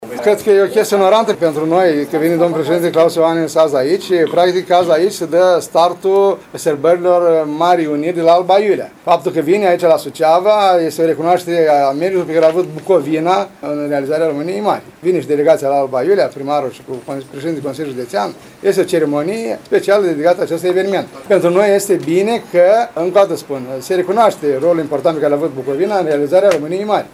Primarul ION LUNGU consideră că vizita preşedintelui este o recunoaştere a meritului pe care l-a avut Bucovina în realizarea României Mari.